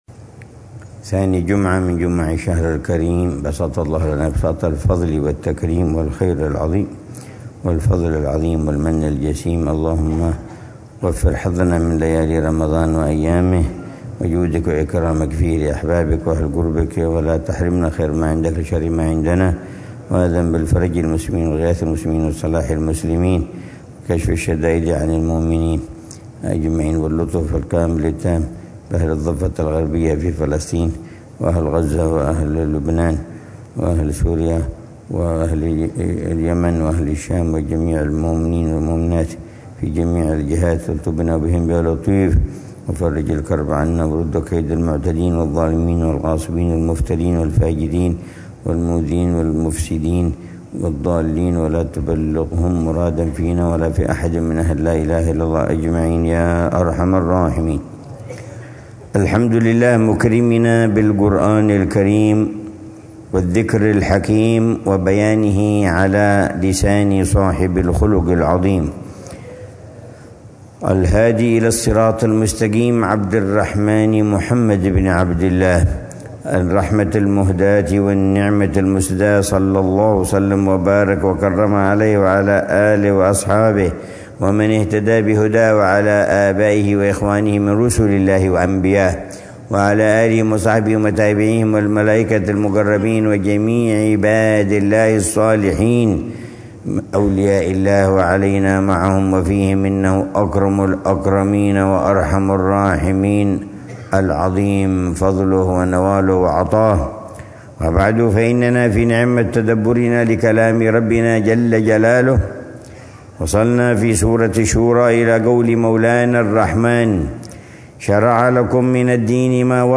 الدرس الرابع من تفسير العلامة عمر بن محمد بن حفيظ للآيات الكريمة من سورة الشورى، ضمن الدروس الصباحية لشهر رمضان المبارك من عام 1446هـ